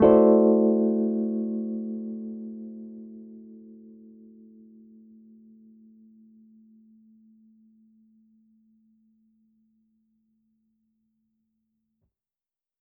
Index of /musicradar/jazz-keys-samples/Chord Hits/Electric Piano 3
JK_ElPiano3_Chord-Am13.wav